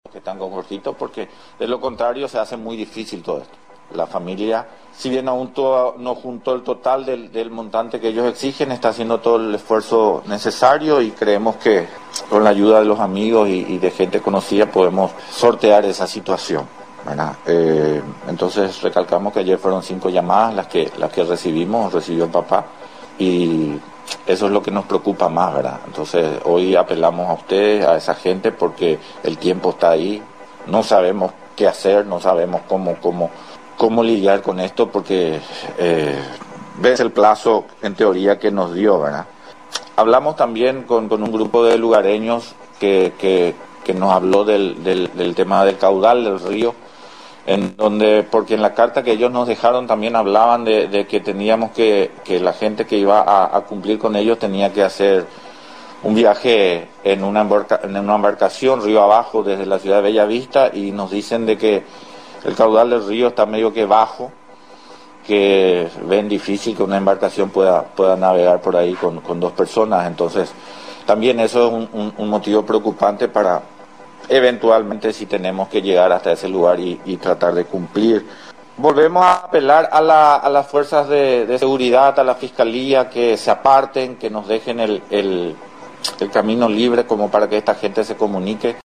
habló en conferencia de prensa al respecto.